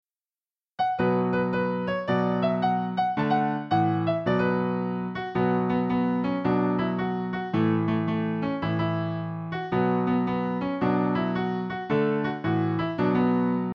Traditional Irish Song Lyrics and Sound Clip